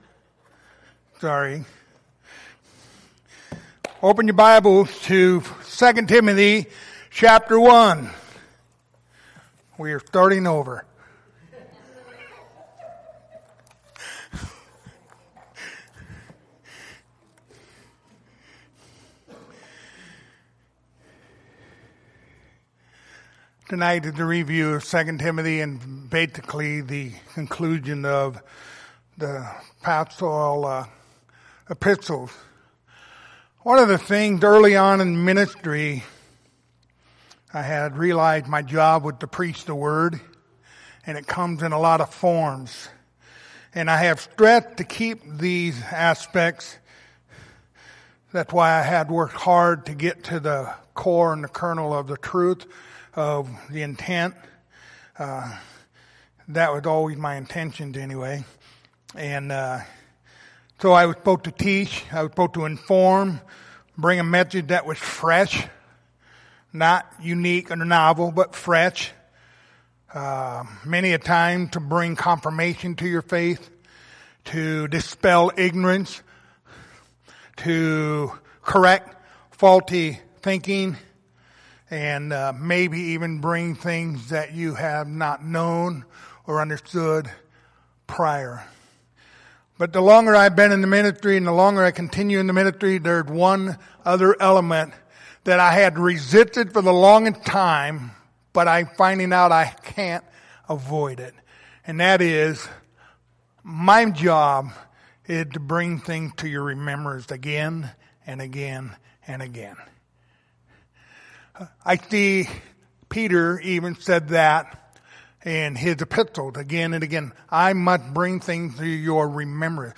Passage: 2 Timothy Service Type: Sunday Evening